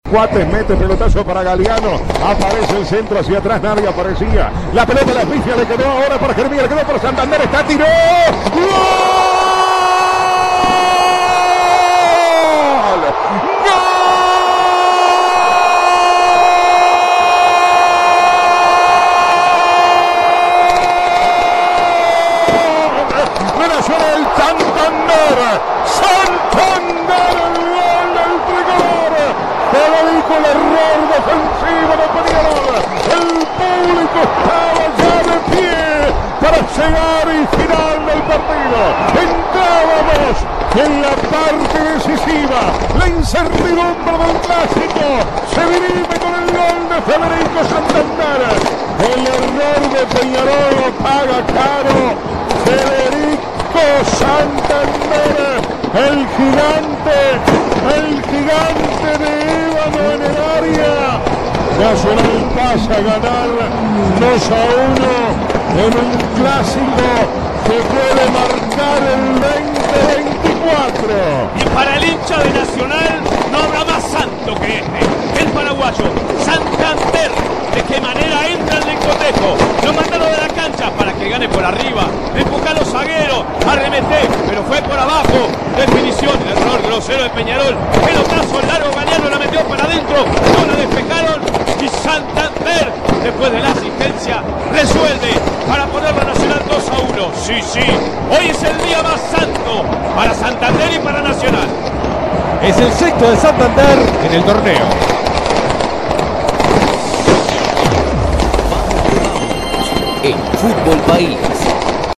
El vibrante triunfo tricolor en la voz del equipo de Vamos que Vamos